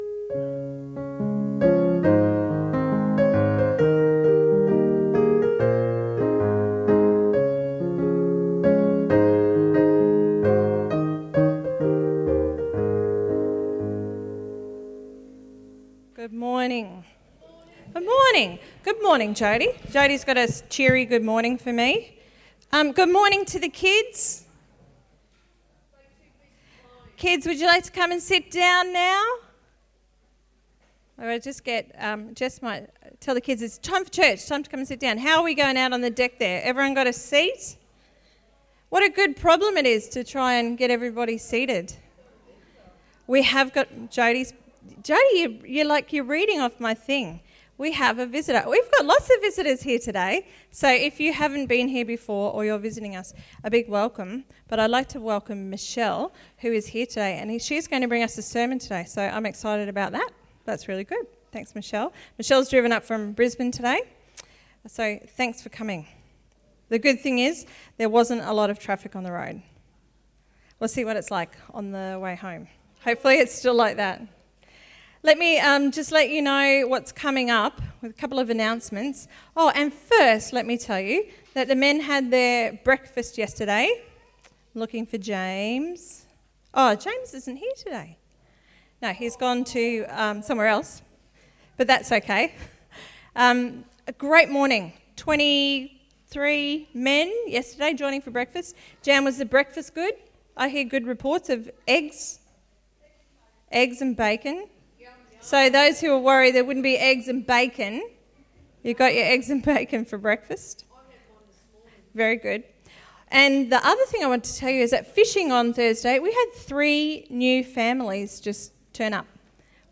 Sunday_Meeting_8th_November_2020_Audio.mp3